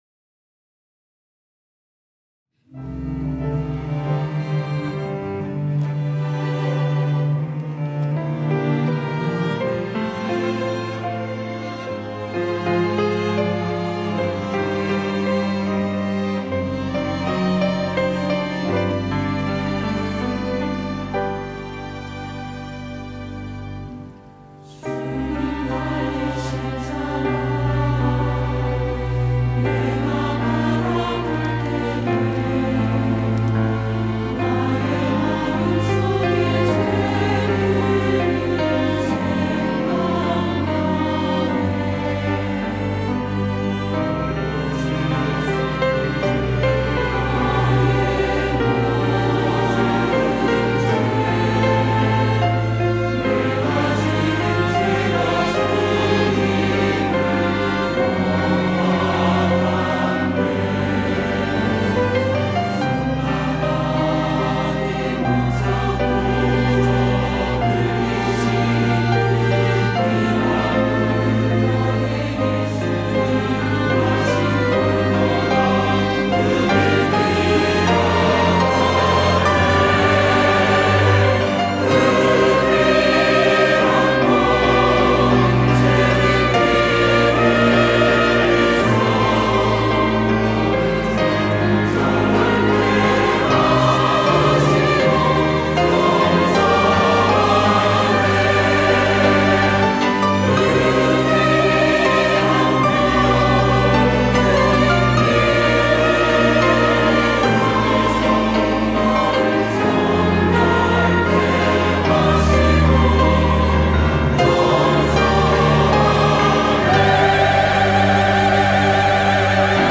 호산나(주일3부) - 회개
찬양대